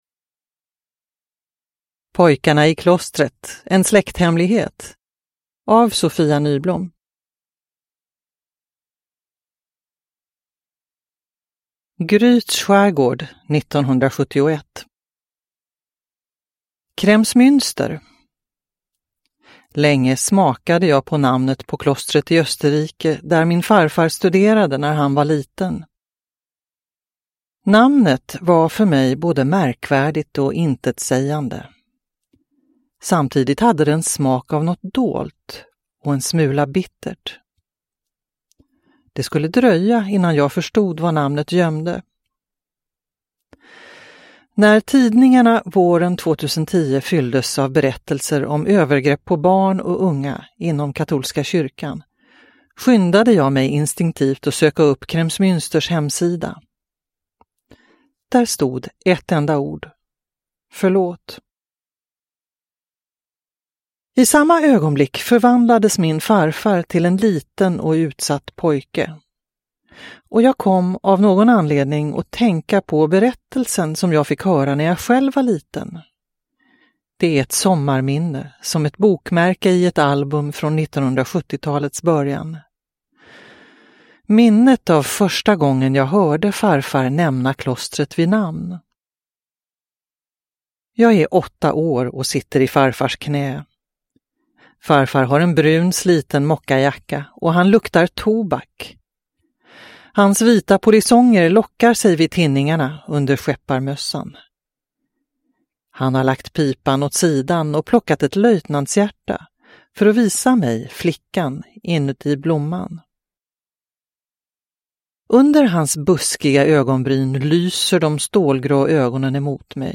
Pojkarna i klostret : en släkthemlighet – Ljudbok – Laddas ner